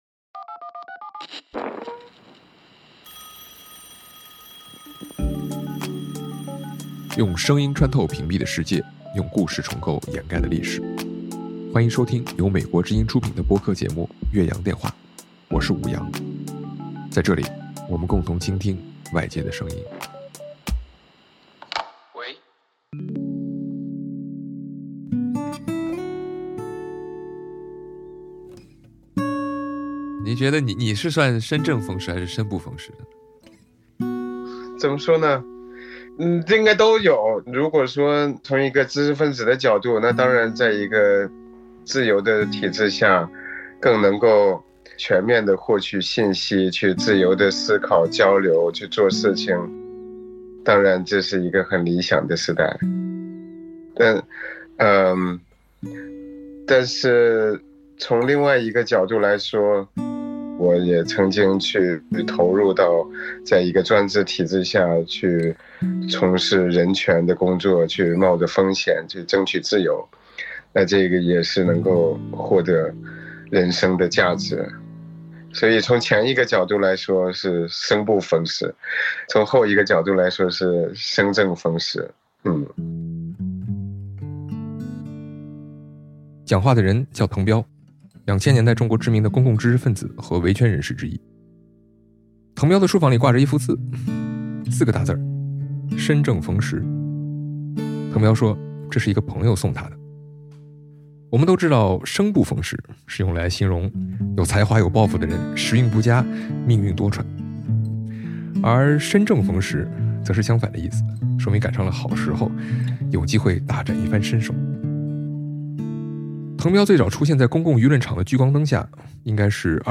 生正逢时的中国公知，在之后的年月中，又将面临什么？ 本期嘉宾：滕彪